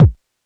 Kicks
DJP_KICK_ (156).wav